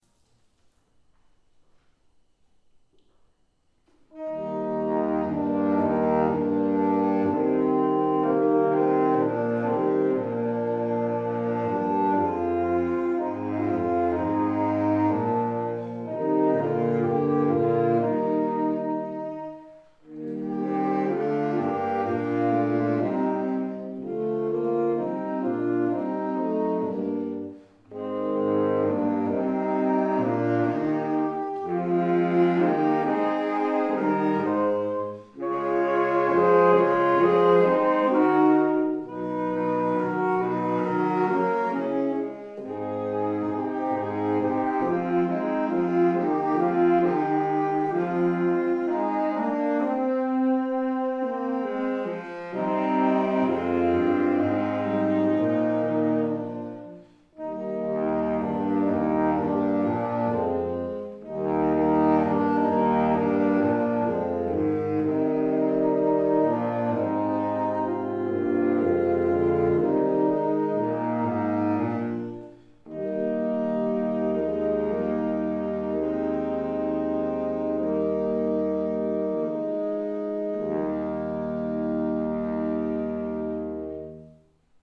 [recorded live at Plattsburgh State University, 2 April 2016]